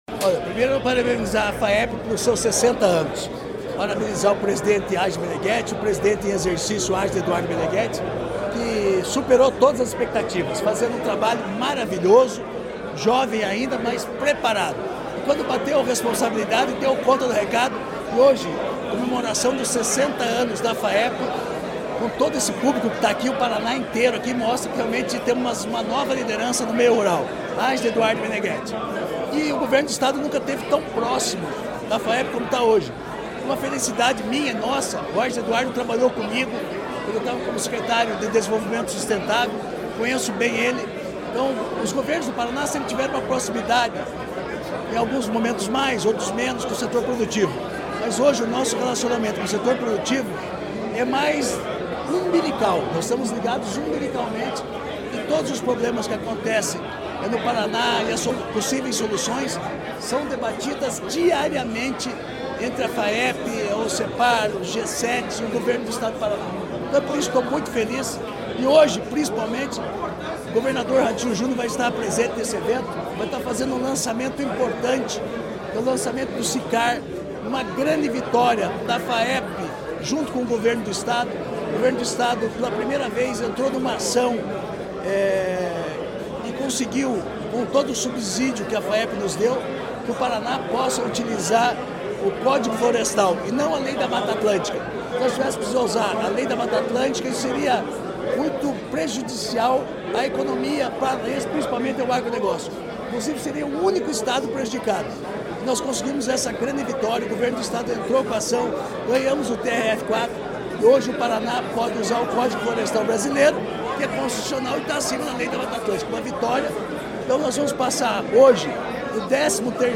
Sonora do secretário da Agricultura e do Abastecimento, Marcio Nunes, sobre o lançamento do programa CertiCAR